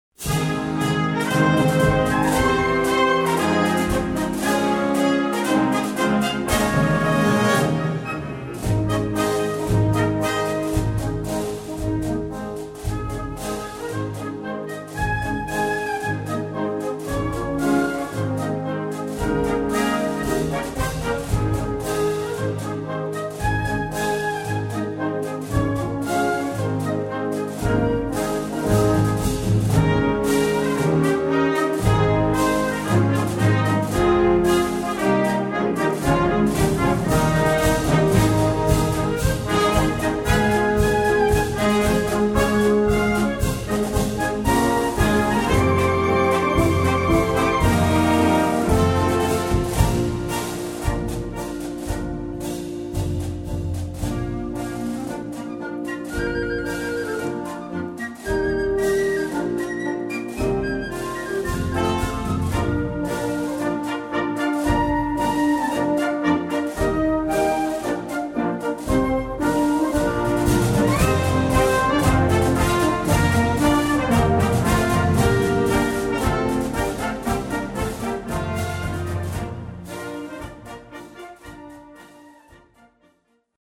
Répertoire pour Harmonie/fanfare